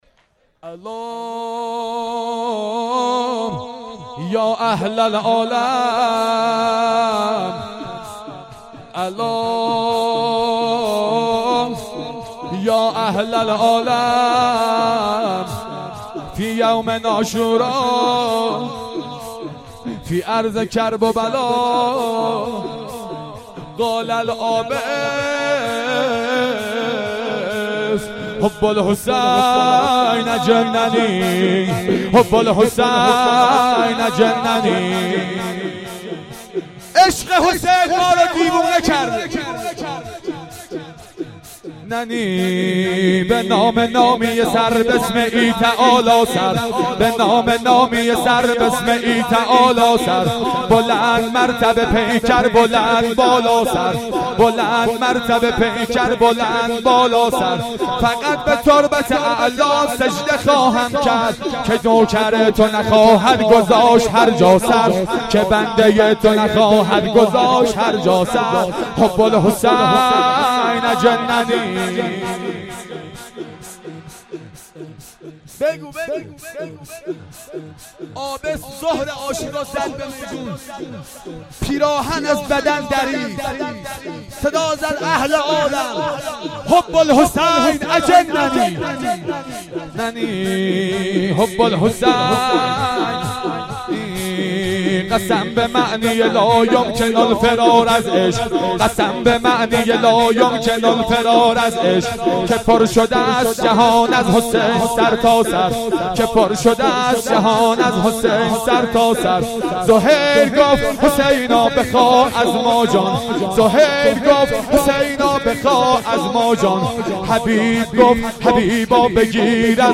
خیمه گاه - منتظران مهدی موعود(عج) - شب سوم - شور - یا اهلل عالم